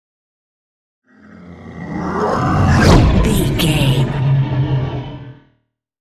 Horror creature vehicle pass by
Sound Effects
In-crescendo
Atonal
ominous
eerie